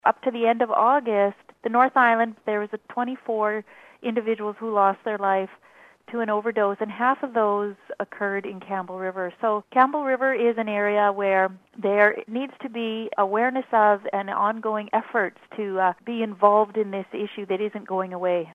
Dr. Charmaine Enns, Medical Officer of Health with Island Health, says it’s a problem in Campbell River: